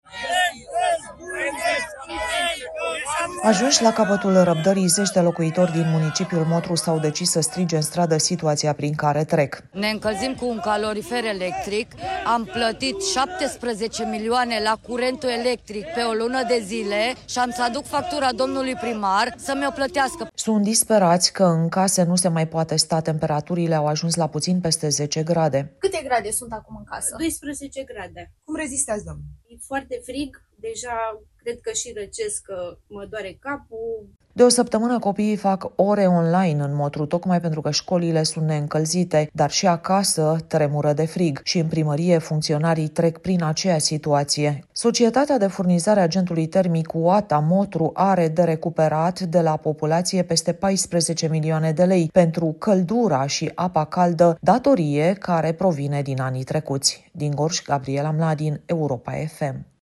Ajunși la capătul răbdării, zeci de locuitori din municipiul Motru s-au decis să strige în stradă situația prin care trec.
„Ne încălzim cu un calorifer electric. Am plătit 17 milioane la curentul electric pe o lună de zile și am să aduc factura domnului primar să mi-o plătească”, a spus o femeie.